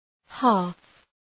Προφορά
{hæf}